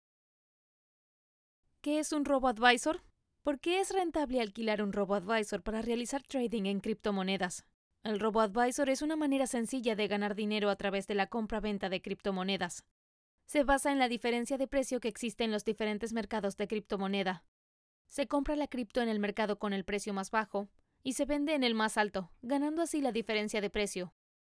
动画解说